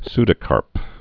(sdə-kärp)